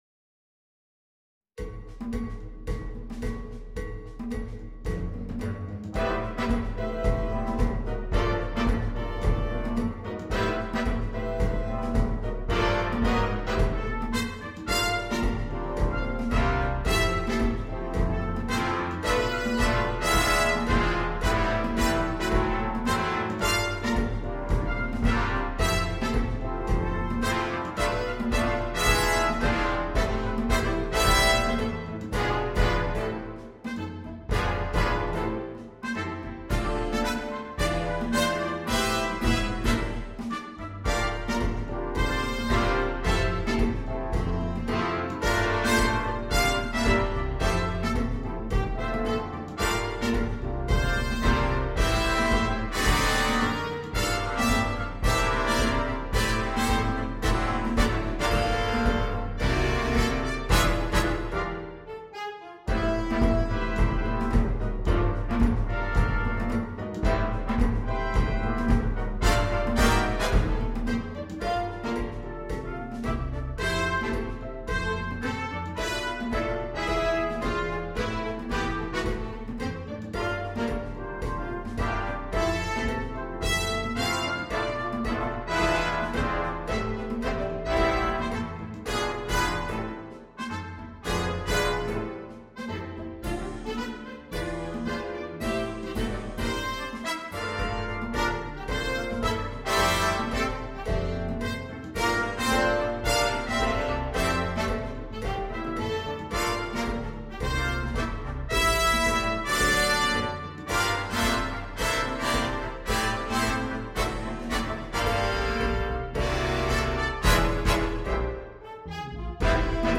на биг-бэнд.